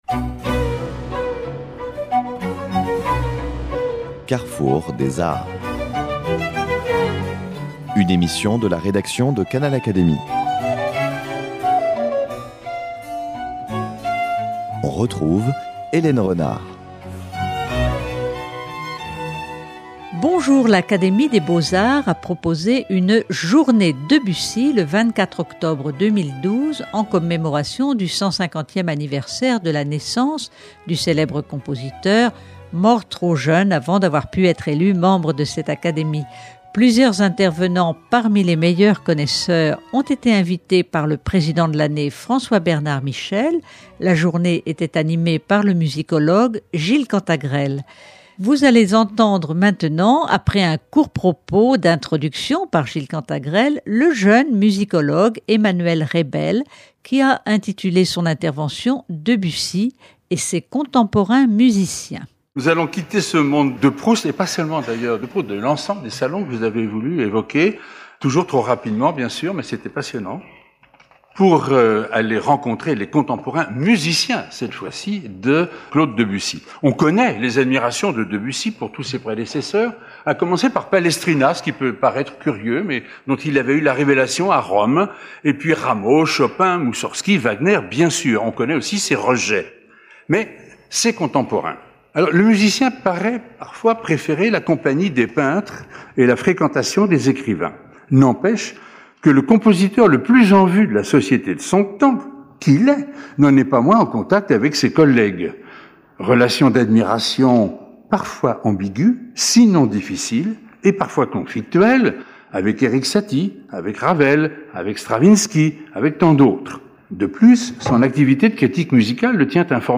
L’Académie des beaux-arts a proposé une "Journée Debussy" le 24 octobre 2012, en commémoration du 150è anniversaire de la naissance du célèbre compositeur, mort